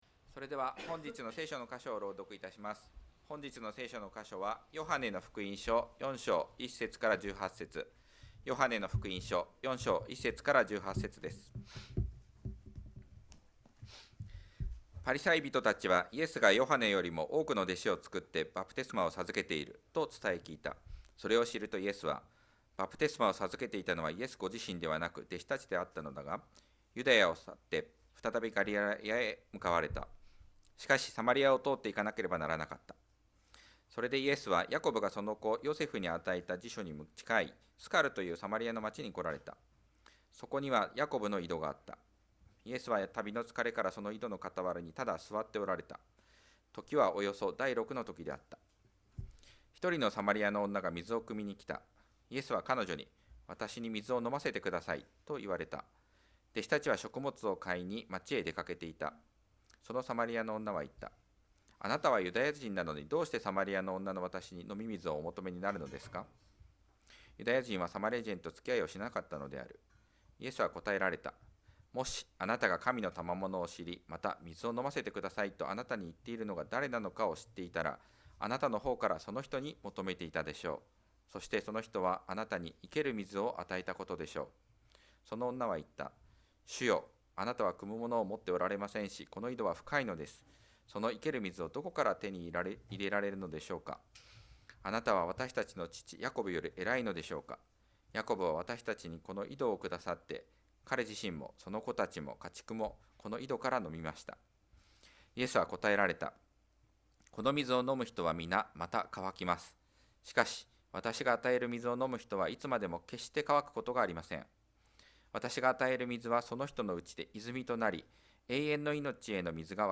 2025年1月19日礼拝 説教 「その水を飲む者はだれでも、また渇きます」 – 海浜幕張めぐみ教会 – Kaihin Makuhari Grace Church